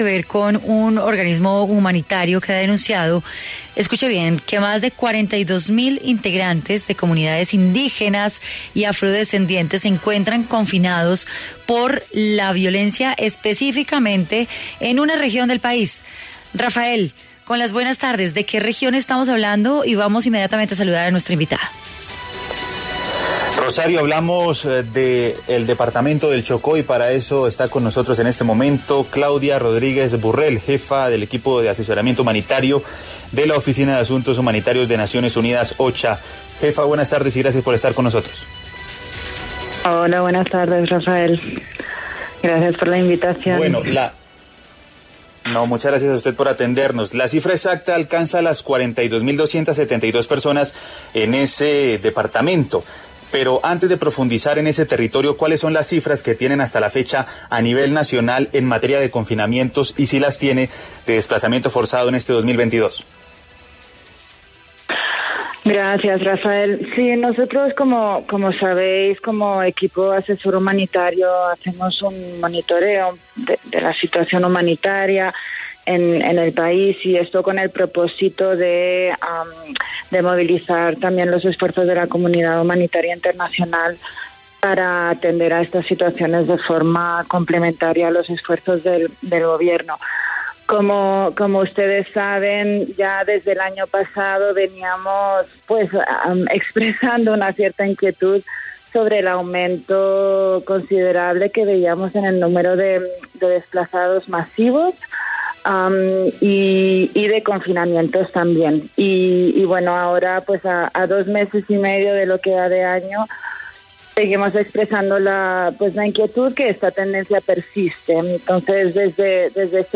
En entrevista con Contrarreloj